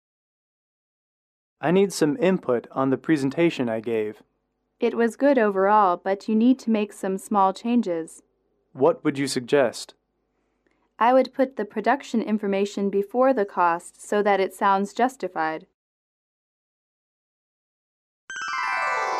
英语口语情景短对话29-2：产品简报(MP3)